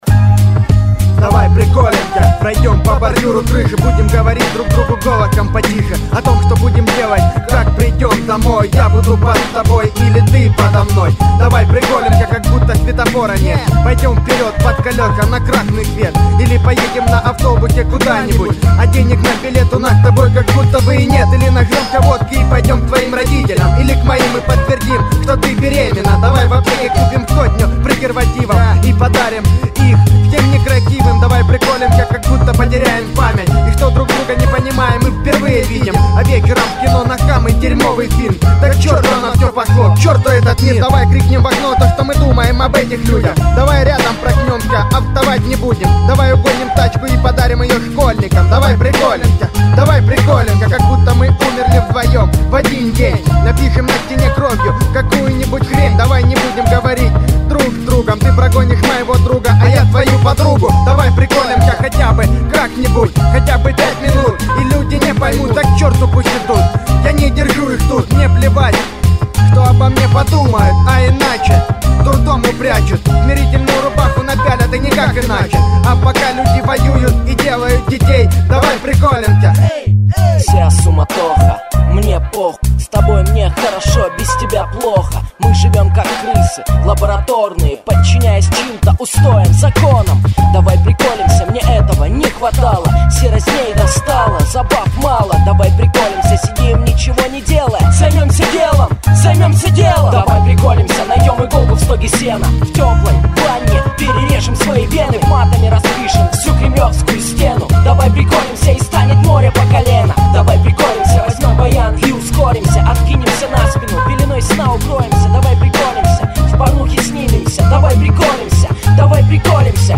Категория: Русский рэп